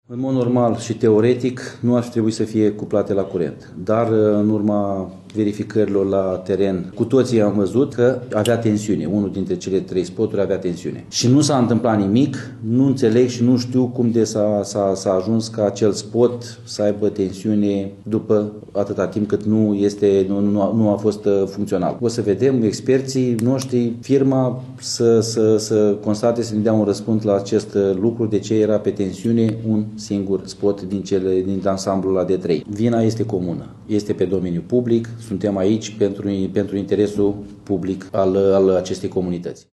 Lucian Braniște, primarul municipiului Vaslui: „În mod normal și teoretic nu ar fi trebuit să fie cuplate la curent”